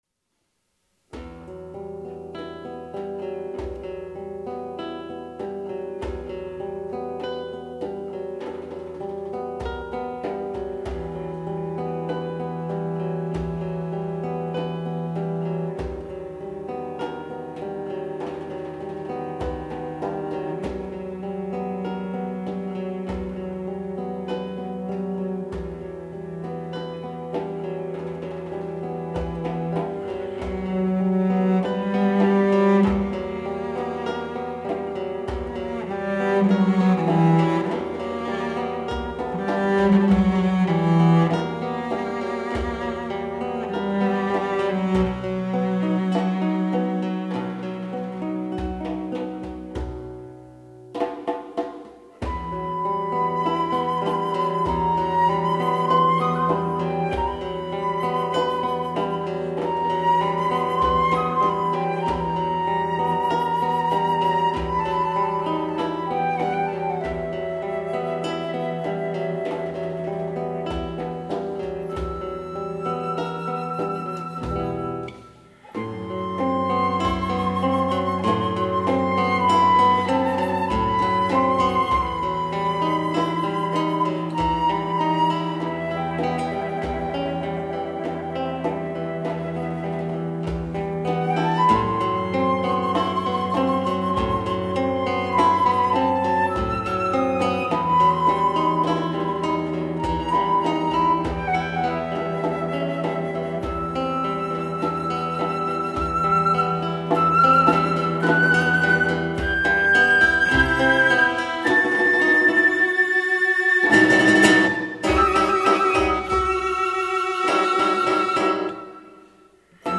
flute, guitar, cello and percussions
live